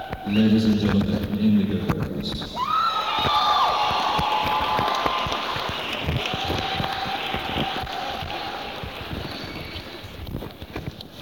(acoustic duo show)